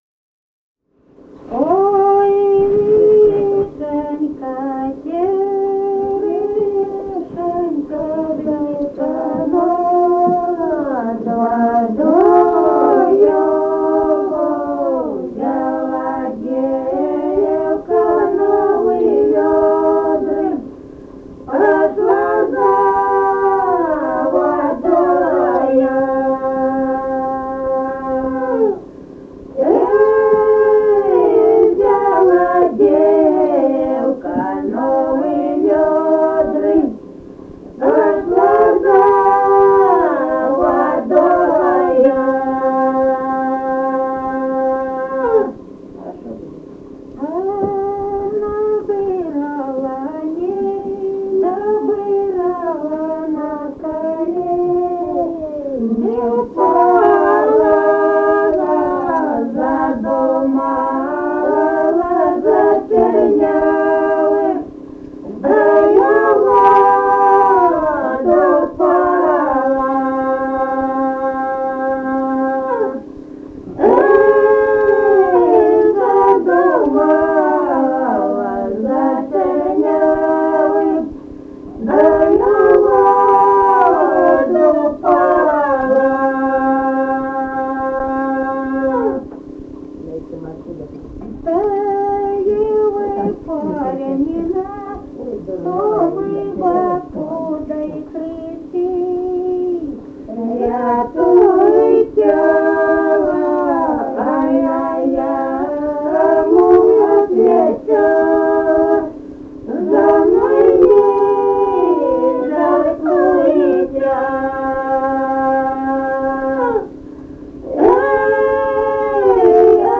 Музыкальный фольклор Климовского района 060. «Ой, вишенька, черешенька» (лирическая).
Записали участники экспедиции